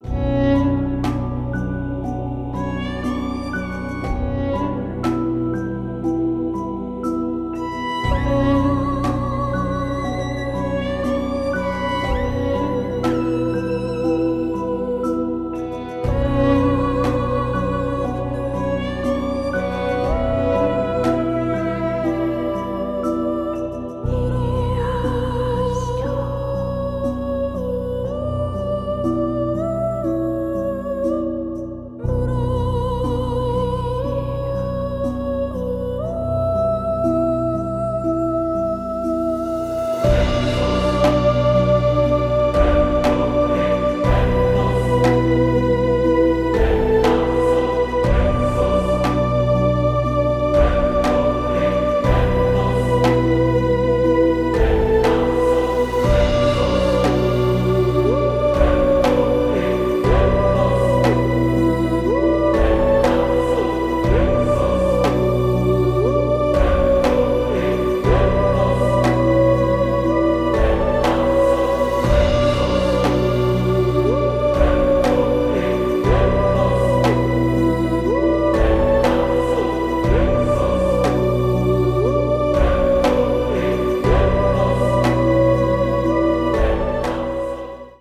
• Качество: 320, Stereo
атмосферные
инструментальные
медленные
Ambient
New Age
мистические
Neoclassical
dark folk
готические
Мистическая композиция на будильник :)